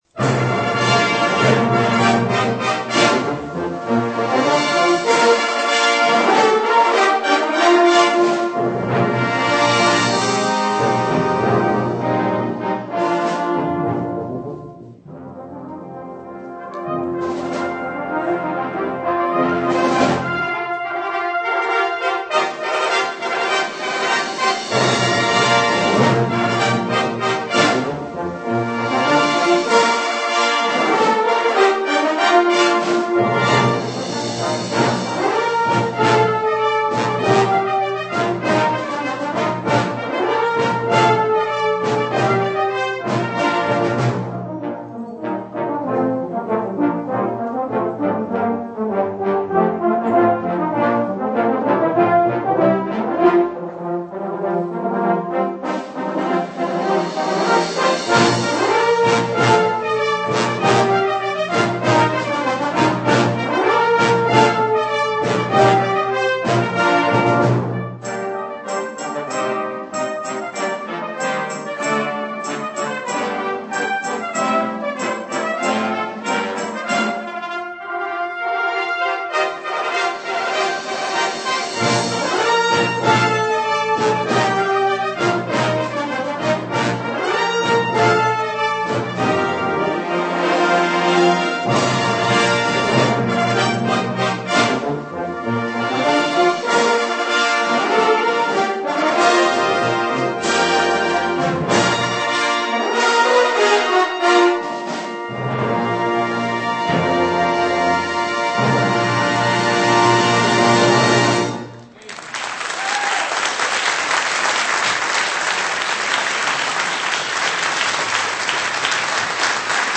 Congregational Song